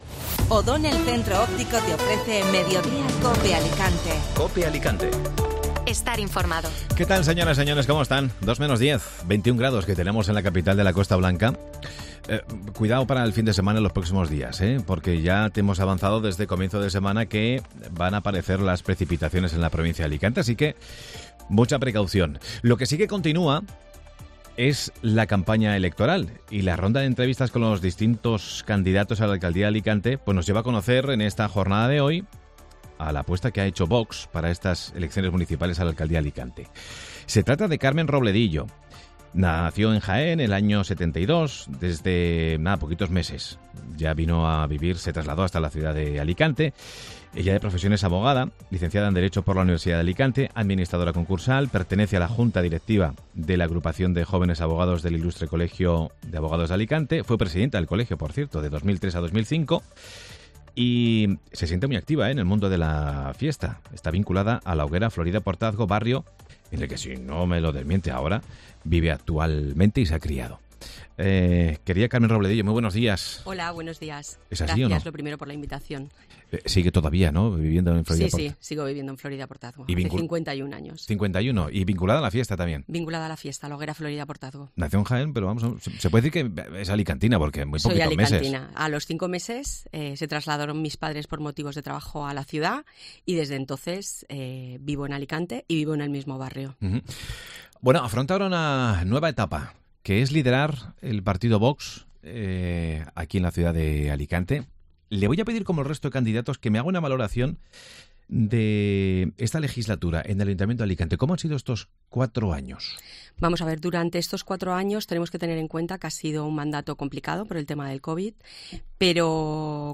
Mediodía COPE Alicante Entrevista